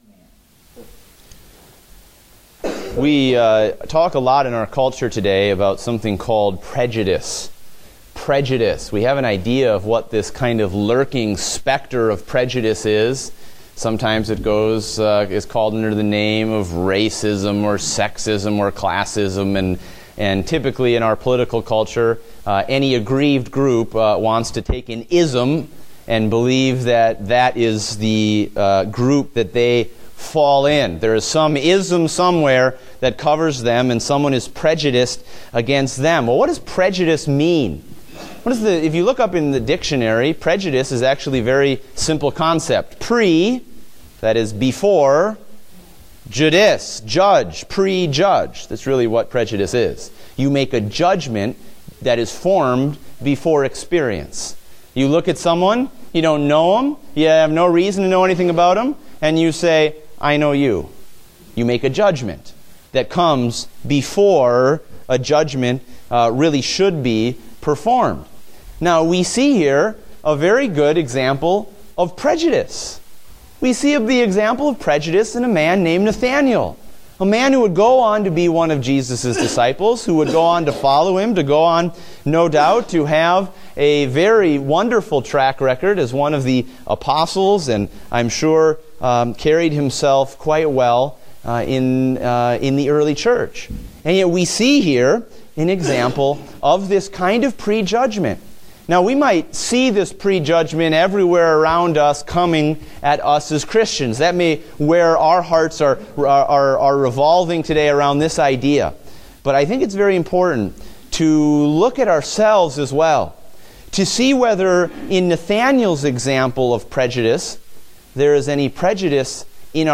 Date: May 8, 2016 (Adult Sunday School)